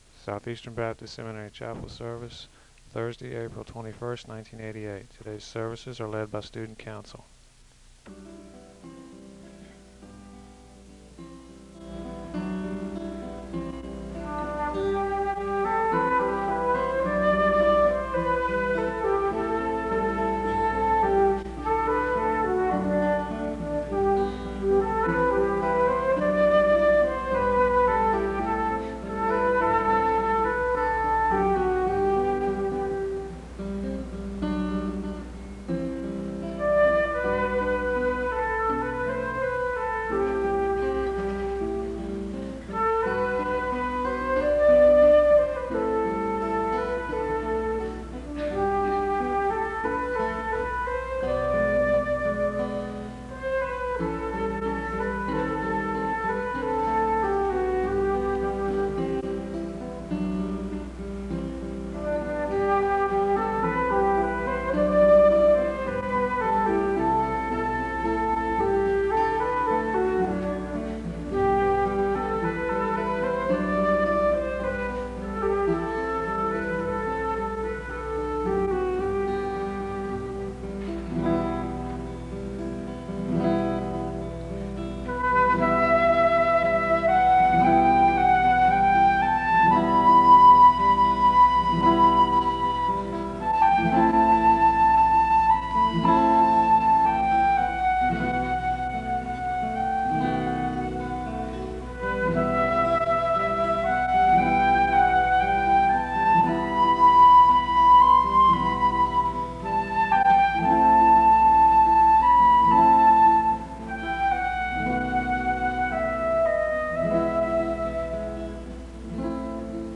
The service begins with music (0:00-3:36).
There is a congregational litany of confession (4:14-5:37). There is a Scripture reading (5:38-8:26).
There is a responsive reading (17:41-20:00). The service concludes with a benediction (20:01-21:36).
SEBTS Chapel and Special Event Recordings SEBTS Chapel and Special Event Recordings